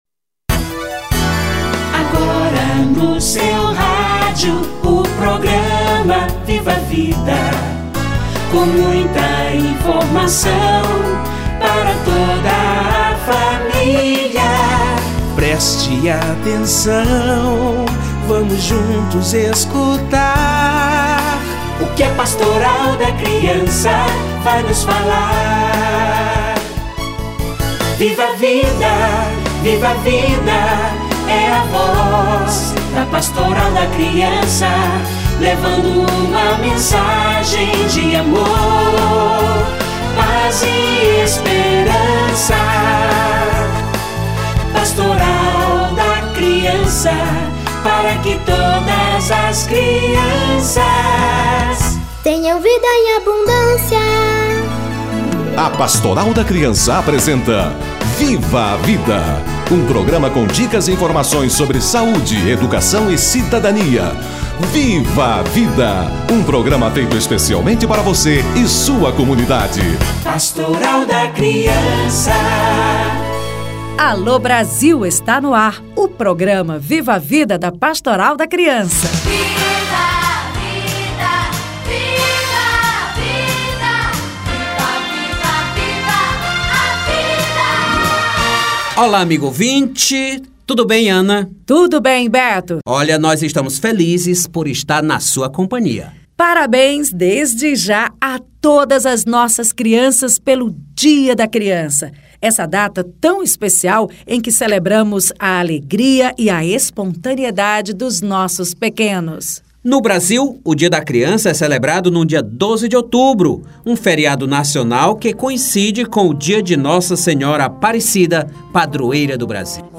Dia da Criança - Entrevista